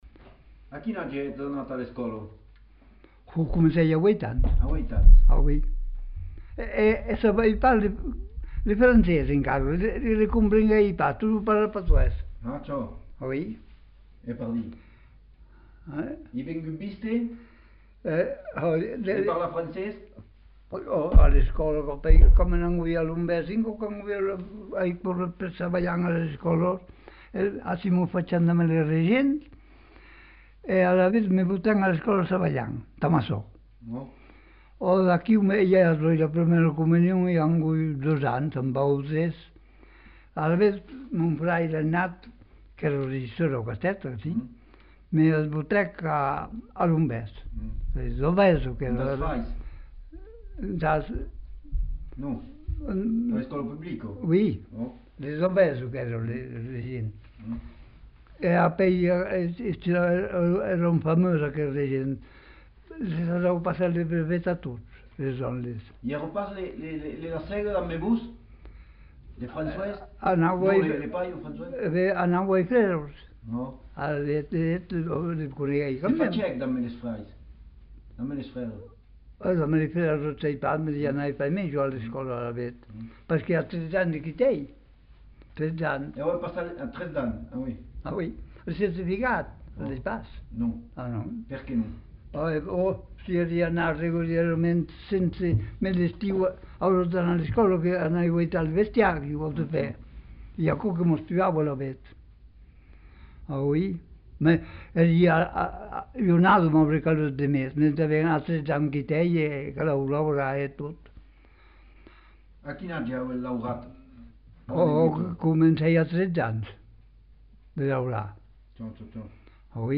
Genre : récit de vie
Contenu dans [enquêtes sonores]